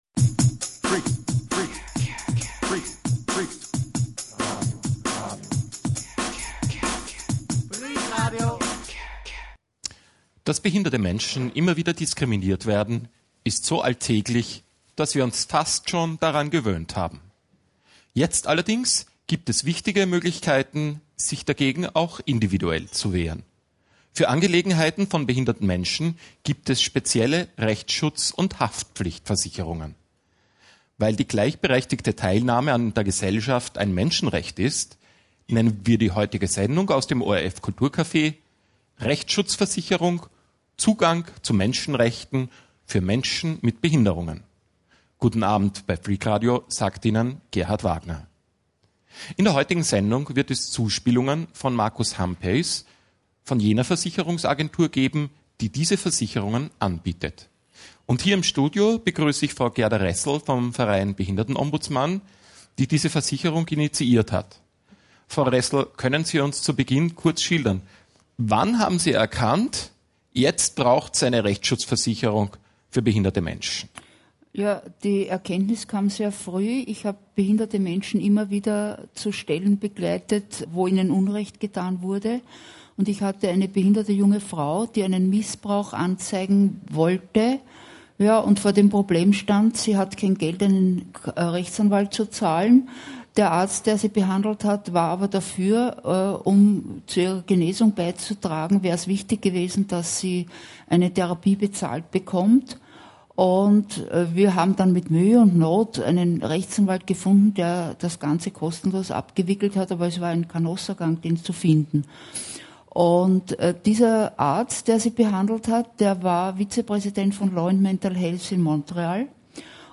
Vertreter der Versicherungen geben einen Überblick über die Leistungen und Anwender berichten über ihre Erfahrungen.